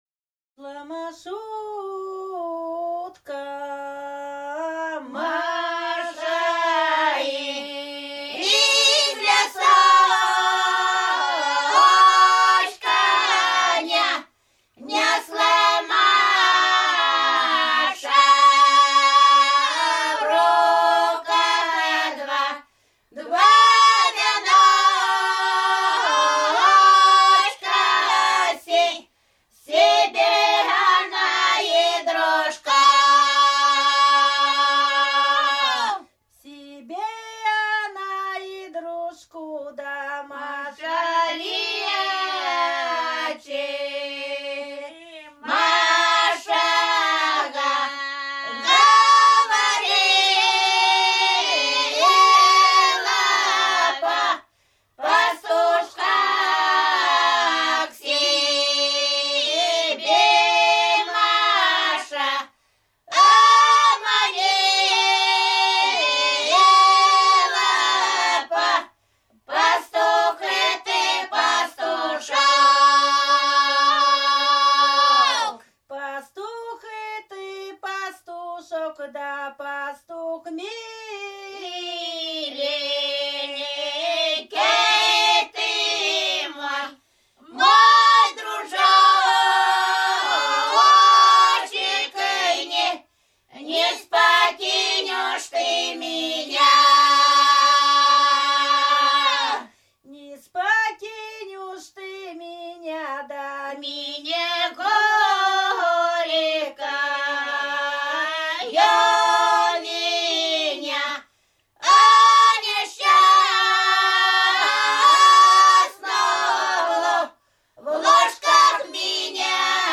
Рязань Кутуково «Шла Машутка», лирическая.